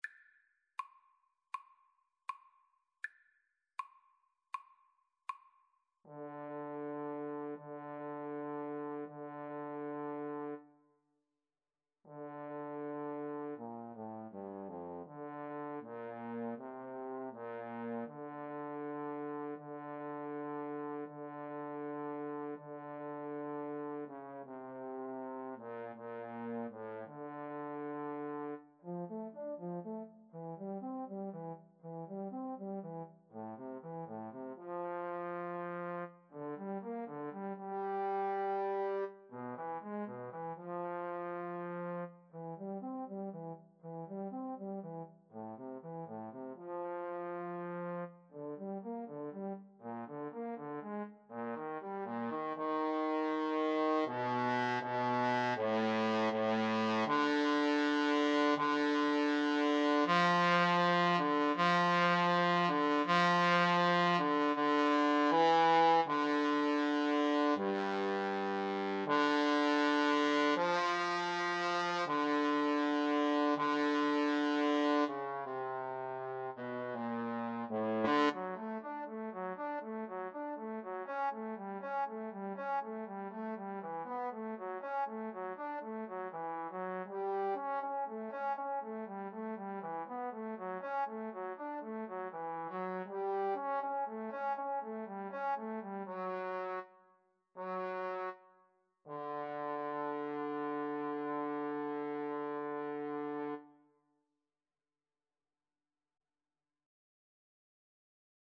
Free Sheet music for Trombone Duet
D minor (Sounding Pitch) (View more D minor Music for Trombone Duet )
Andante = 80
Classical (View more Classical Trombone Duet Music)